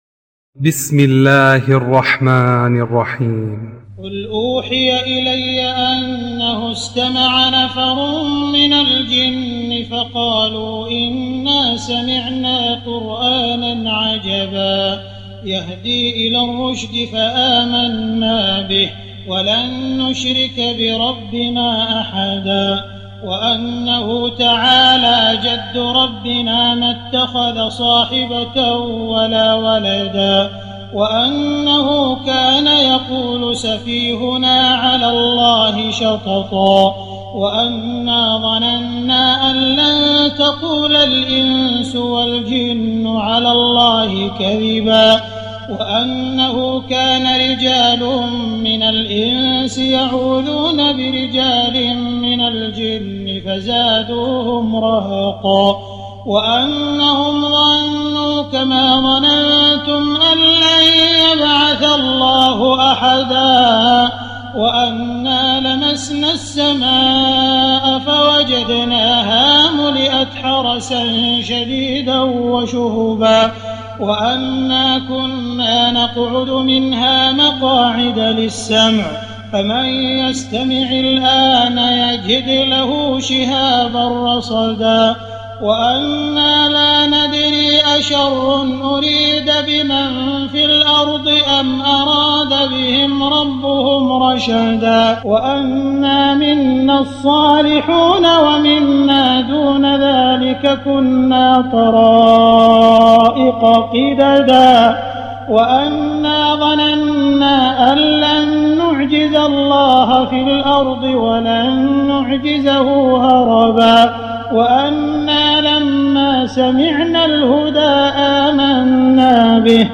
تراويح ليلة 28 رمضان 1419هـ من سورة الجن الى المرسلات Taraweeh 28 st night Ramadan 1419H from Surah Al-Jinn to Al-Mursalaat > تراويح الحرم المكي عام 1419 🕋 > التراويح - تلاوات الحرمين